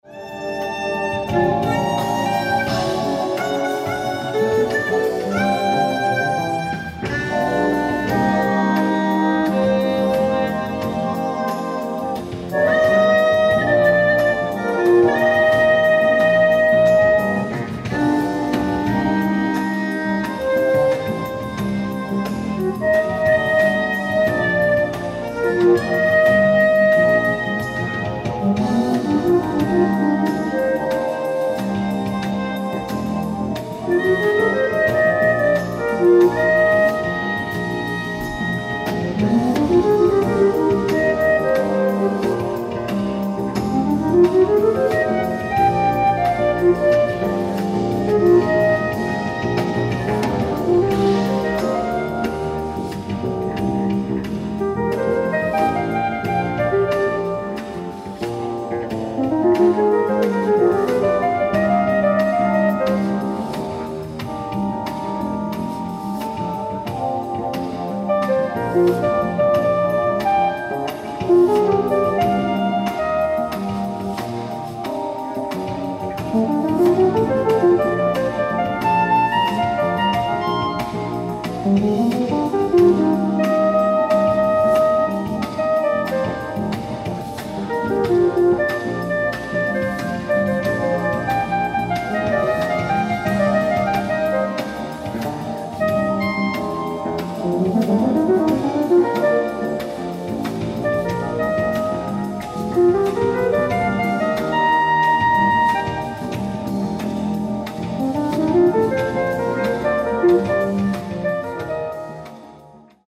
ライブ・アット・オーディトリアム・シアター、シカゴ 07/09/1982
※試聴用に実際より音質を落としています。